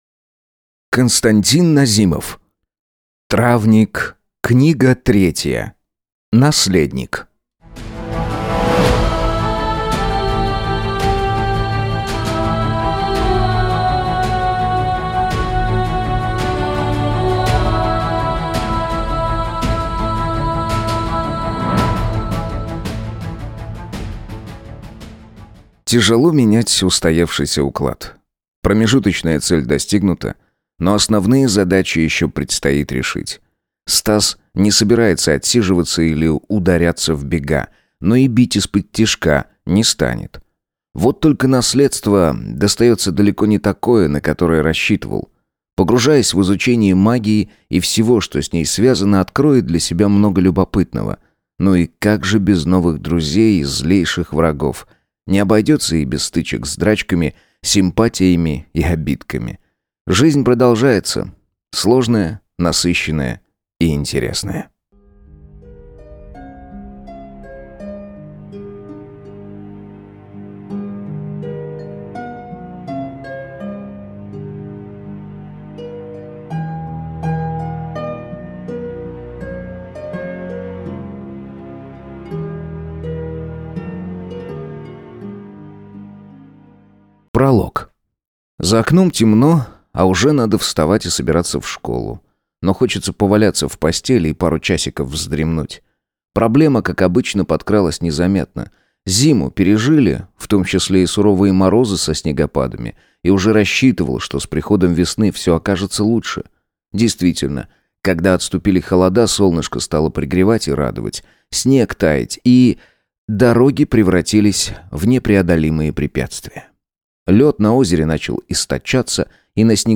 Аудиокнига Травник 3. Наследник | Библиотека аудиокниг